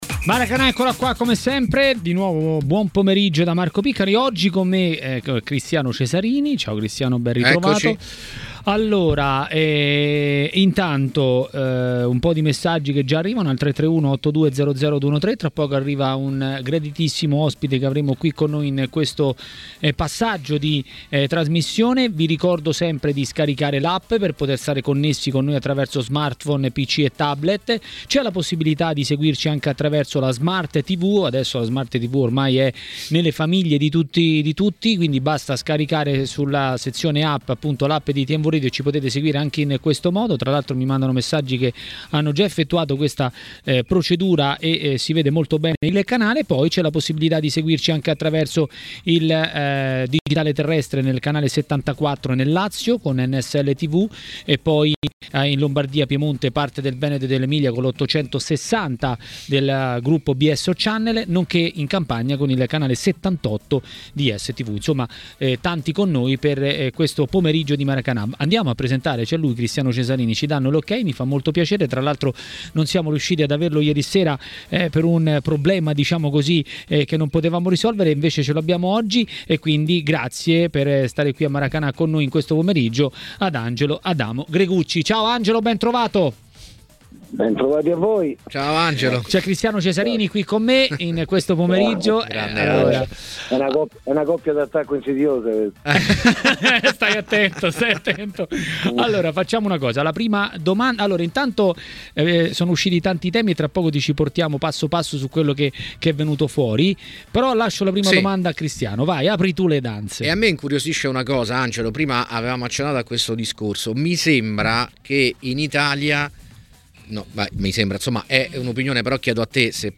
A TMW Radio, durante Maracanà, è intervenuto l'ex calciatore e tecnico Angelo Gregucci.